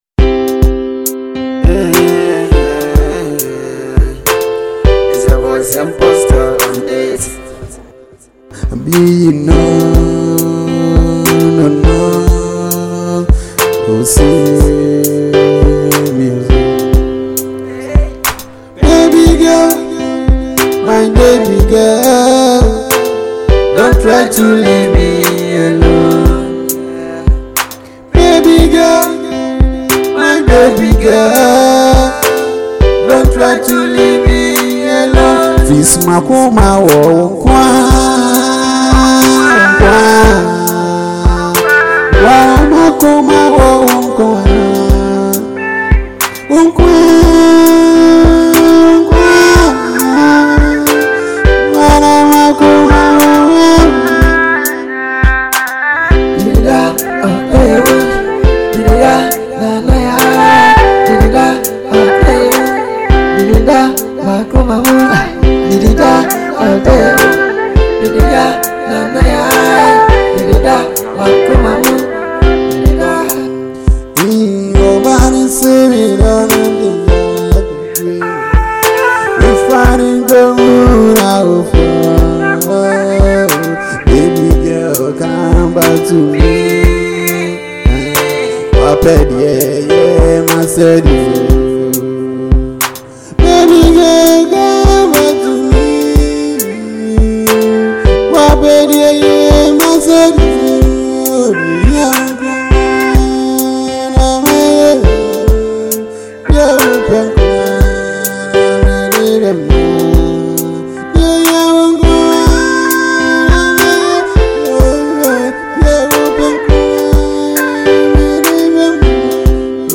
new love tune